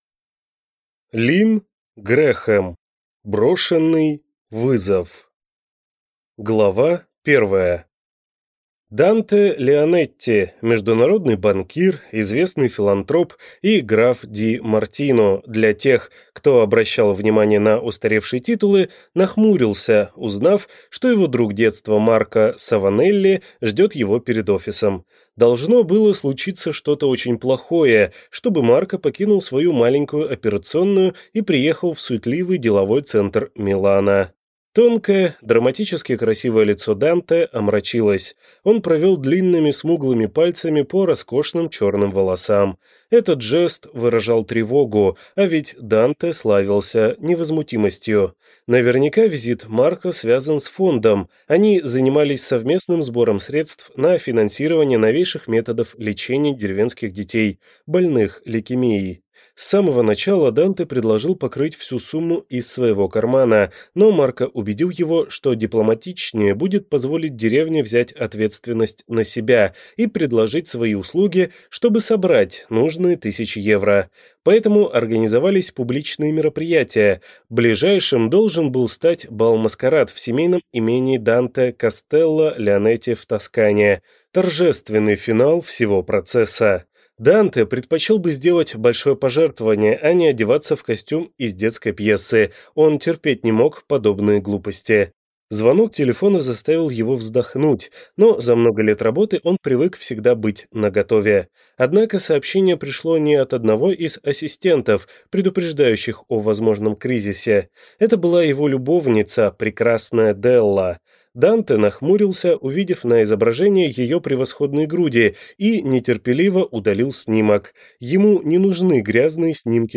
Аудиокнига Брошенный вызов | Библиотека аудиокниг
Aудиокнига Брошенный вызов Автор Линн Грэхем